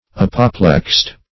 Apoplexed \Ap`o*plexed\ (-pl[e^]kst), a. Affected with apoplexy.